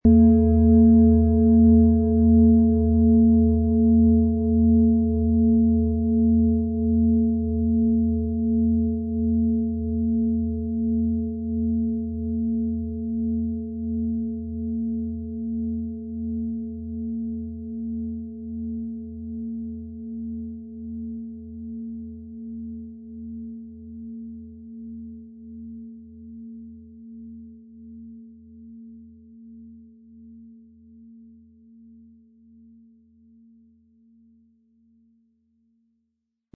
Planetenschale® Innere Tiefe fühlen & Emotionen wahrnehmen mit Alphawellen & Mond, Ø 28 cm inkl. Klöppel
• Mittlerer Ton: Mond
Sie möchten den Original-Ton der Schale hören? Klicken Sie bitte auf den Sound-Player - Jetzt reinhören unter dem Artikelbild.
Sanftes Anspielen mit dem gratis Klöppel zaubert aus Ihrer Schale berührende Klänge.
PlanetentöneAlphawelle & Mond
MaterialBronze